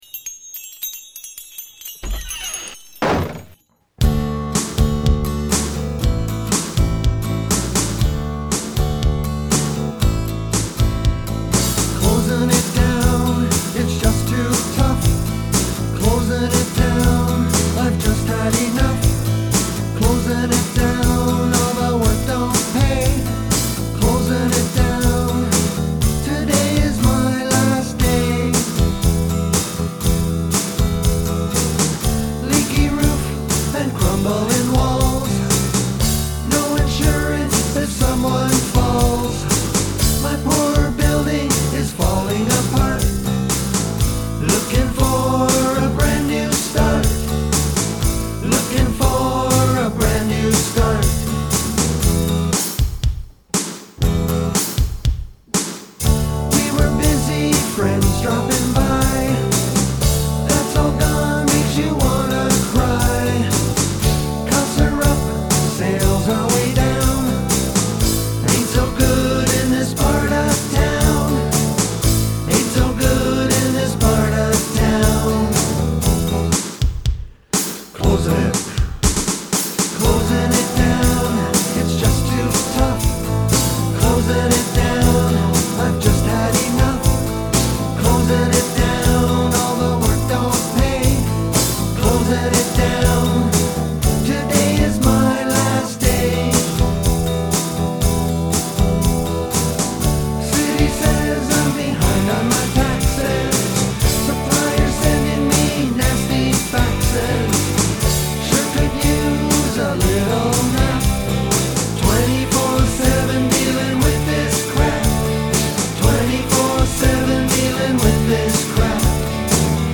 harmonica
Lead & Background Vocals
lead & rhythm guitars; acoustic & electric
bass guitar
keyboards
percussion